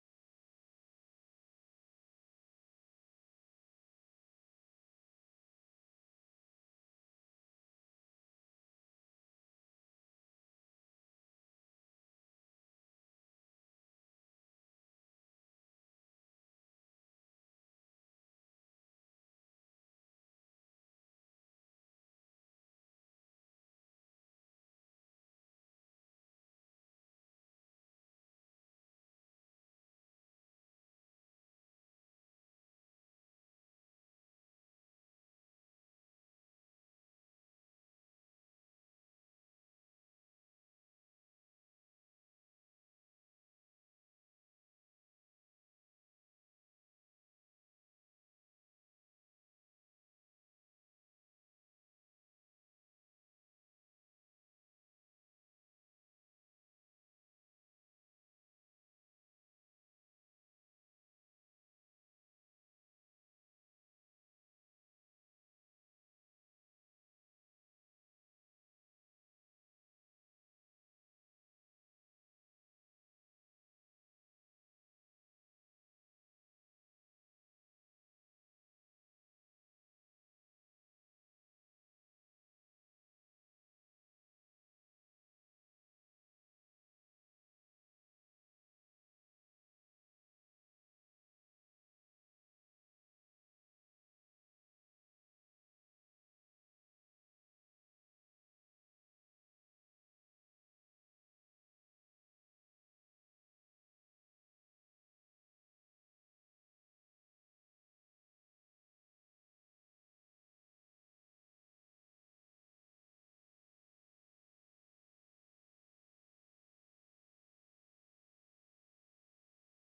Sermons from the morning and evening worship services of church of Christ in Olive Branch, Mississippi USA.